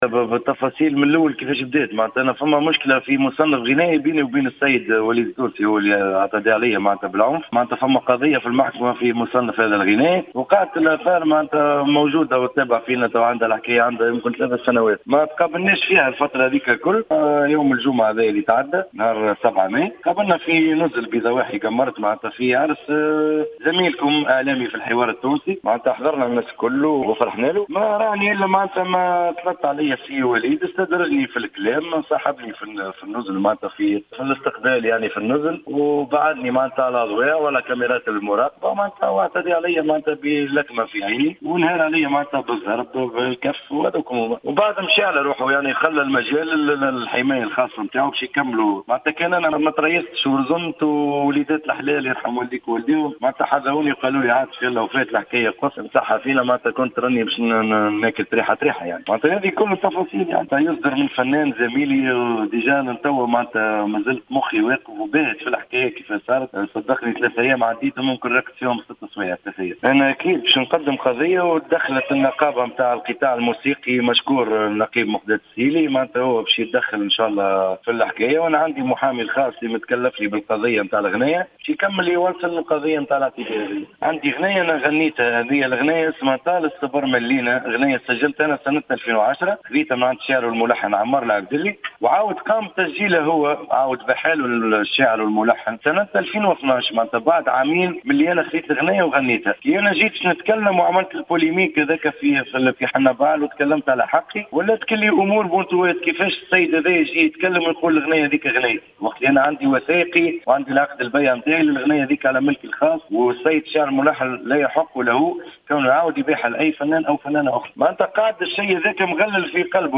في تصريح للجوهرة أف أم